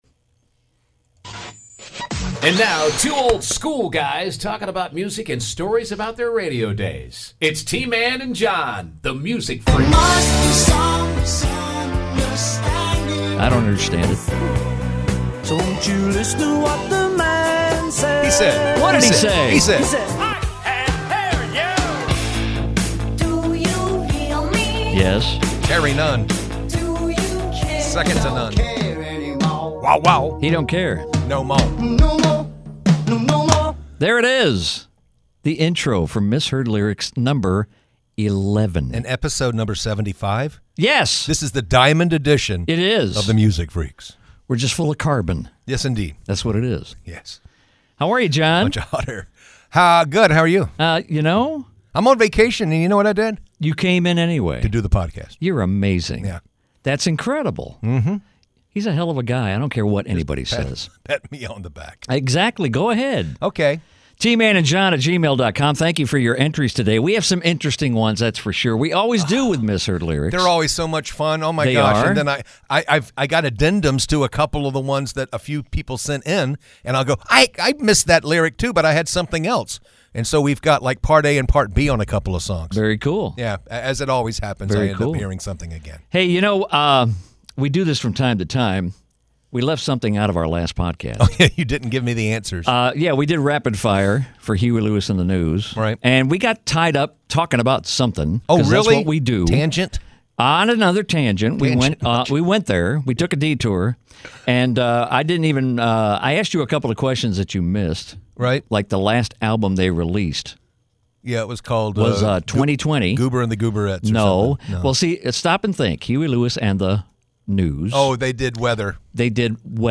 2 North Florida radio guys that truly love music and the radio of the past few decades, of which they have both been a part.